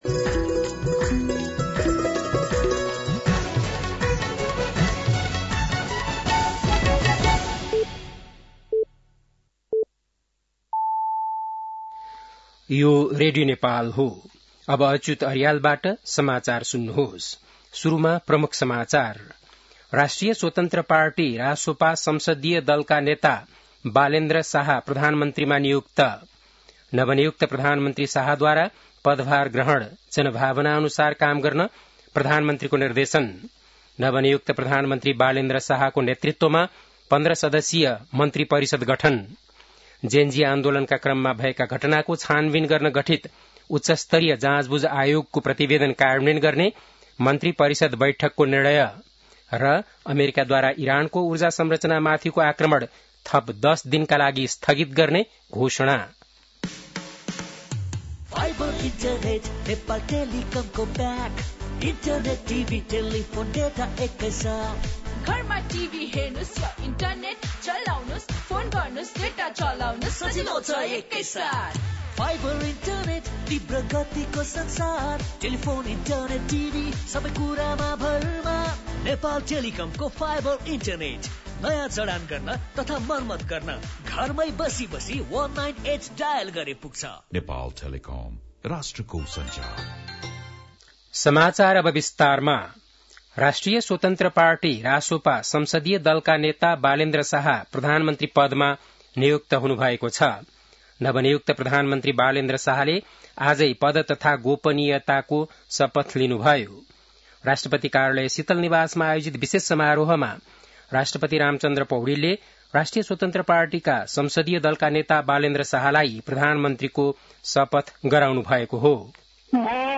बेलुकी ७ बजेको नेपाली समाचार : १३ चैत , २०८२